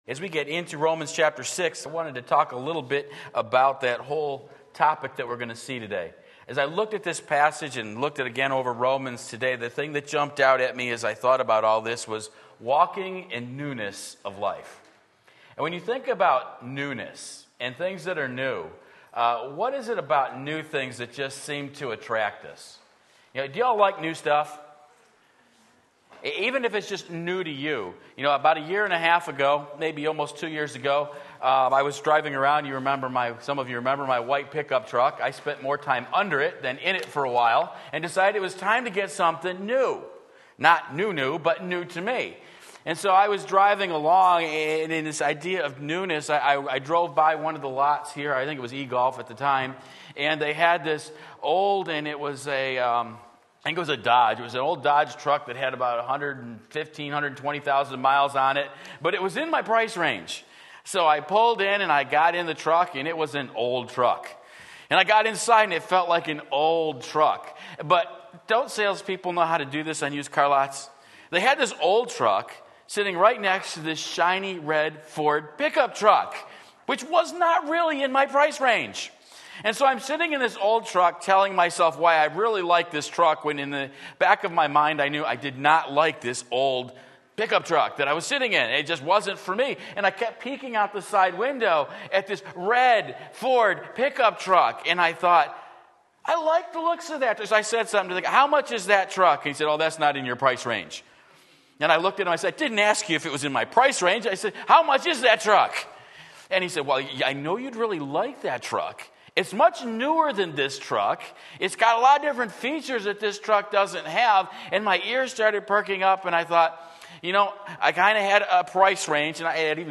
Walk in Newness of Life Romans 6:6-10 Sunday Morning Service